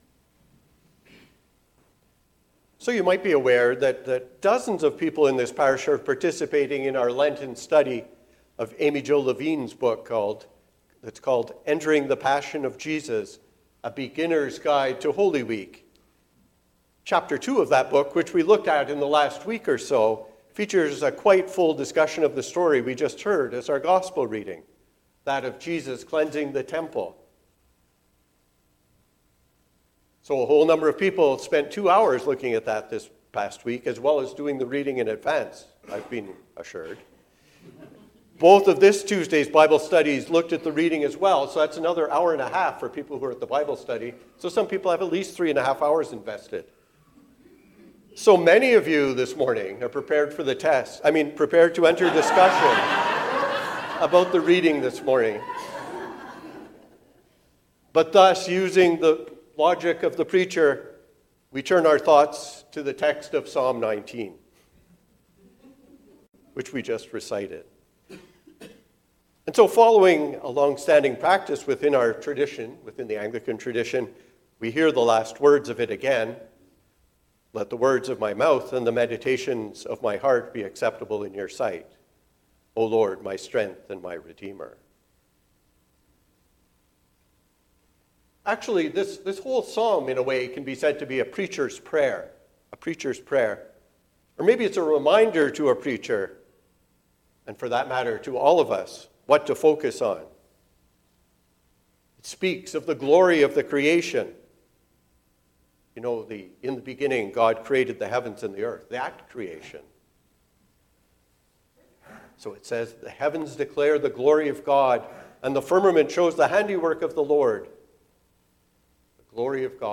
Holy Priorities. A sermon for the Third Sunday in Lent